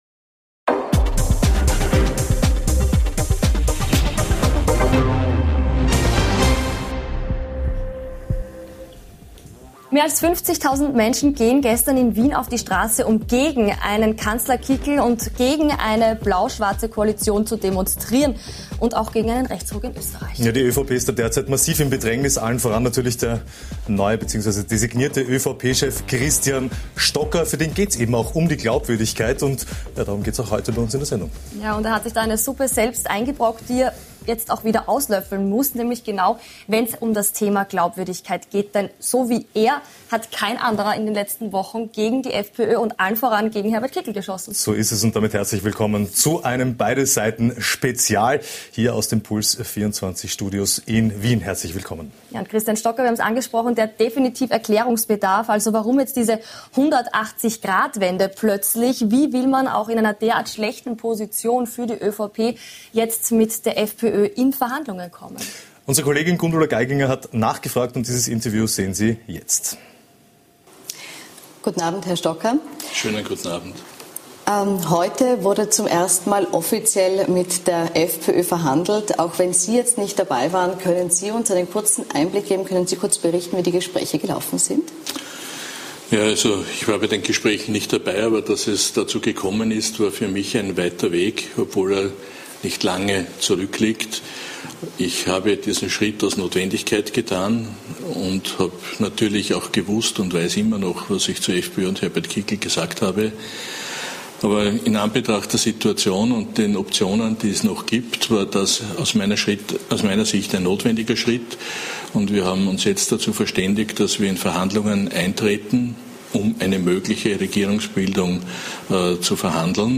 Spezial: Christian Stocker (ÖVP) im großen Interview ~ Beide Seiten Live Podcast